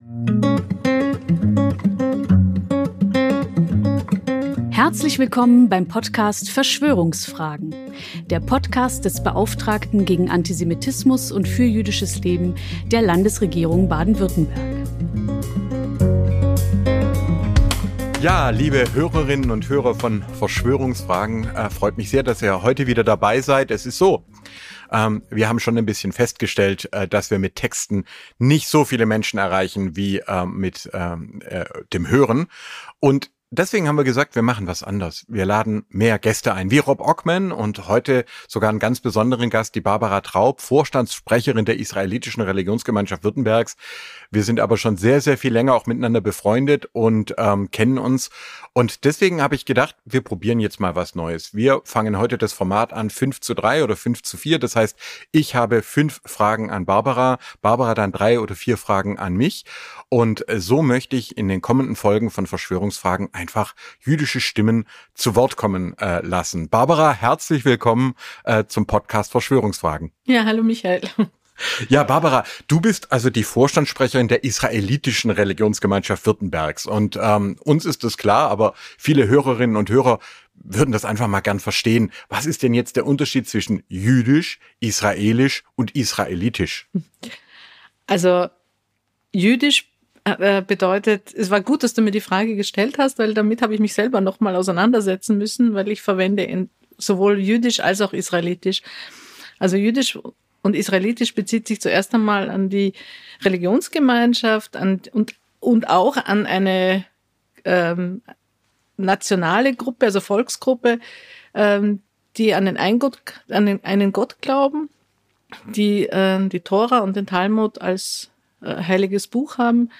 1 Ep. 49 | Dankesrede von Dr. Michael Blume zur Verleihung der Otto-Hirsch-Auszeichnung 2022 37:14